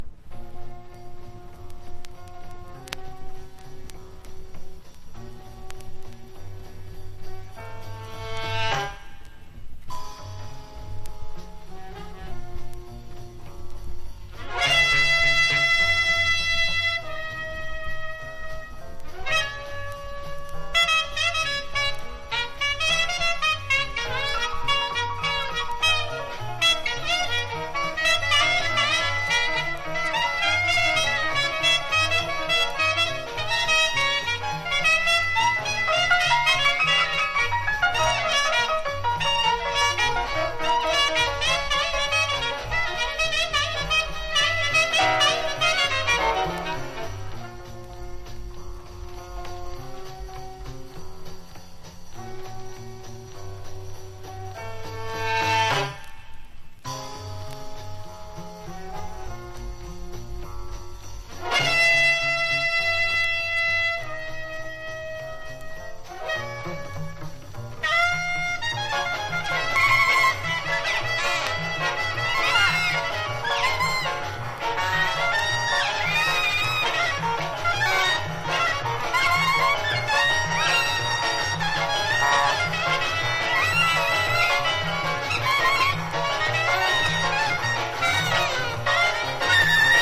# FREE / SPIRITUAL# ハードバップ